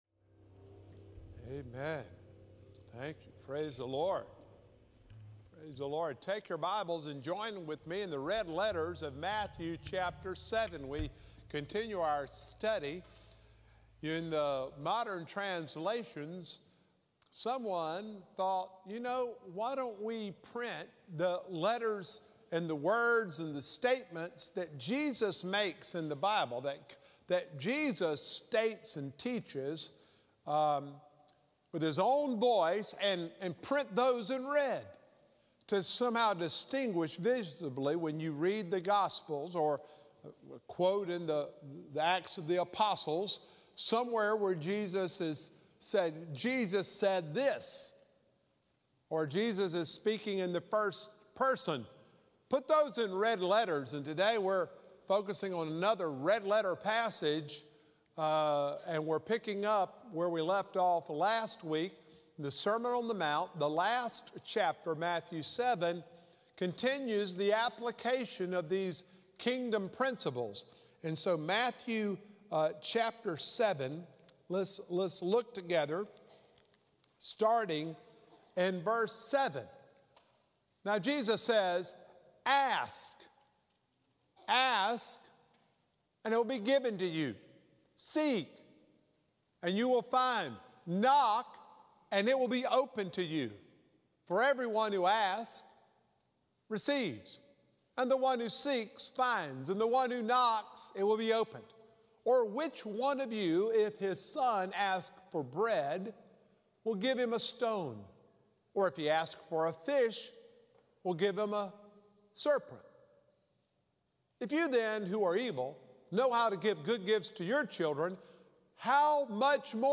Sermons
June-2-2024-Sermon-Audio.mp3